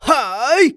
xys发力4.wav 0:00.00 0:00.77 xys发力4.wav WAV · 66 KB · 單聲道 (1ch) 下载文件 本站所有音效均采用 CC0 授权 ，可免费用于商业与个人项目，无需署名。